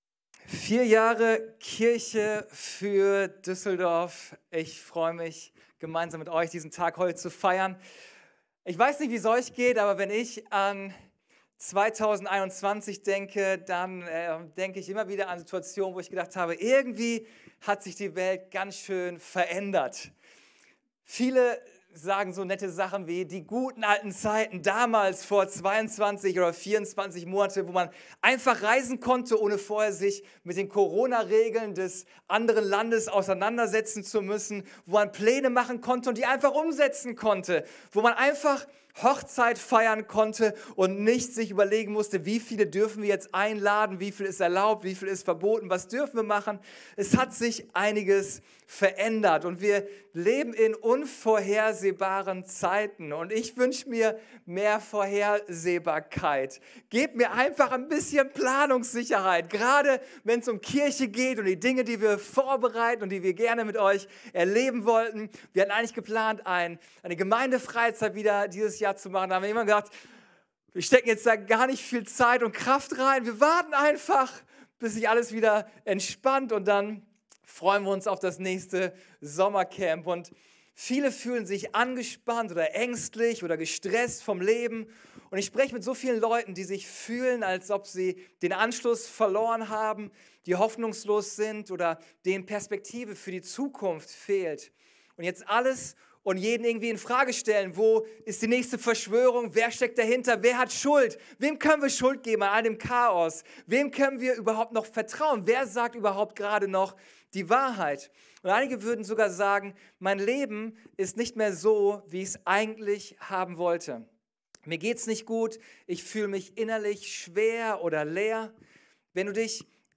Unsere Predigt vom 20.02.22 Folge direkt herunterladen